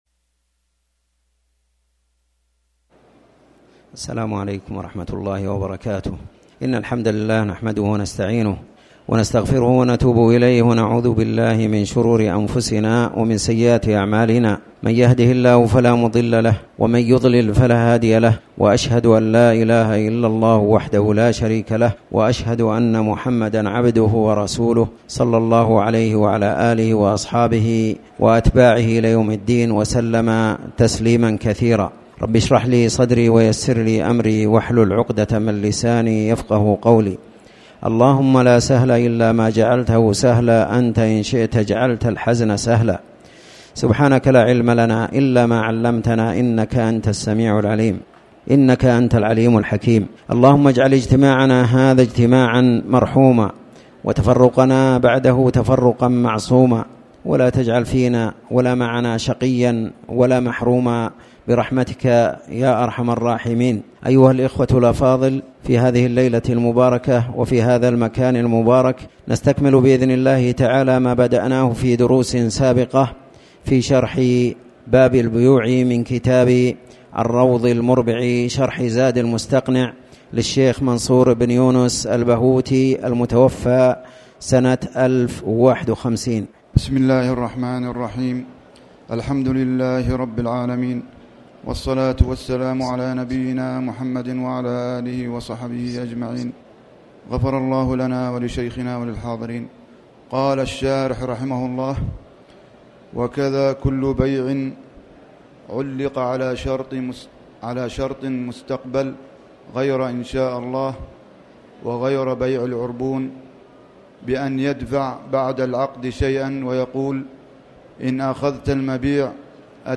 تاريخ النشر ١٧ رجب ١٤٣٩ هـ المكان: المسجد الحرام الشيخ